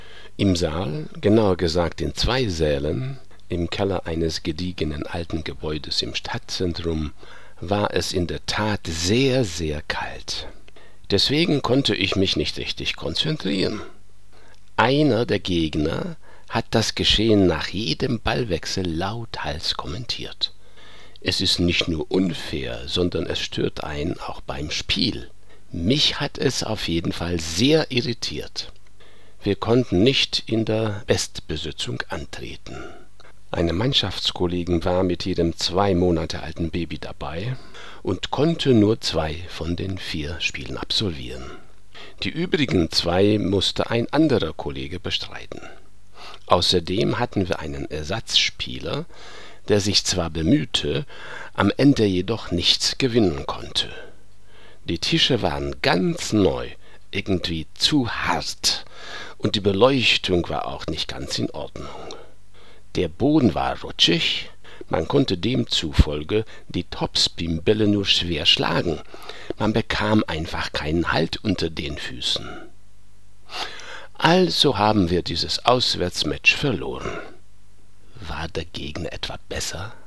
Text zum Anhören (Aussprache üben, das Resultat der Gruppe zeigen, evtl. mir zuschicken. Ich freue mich schon auf diese Versuche.)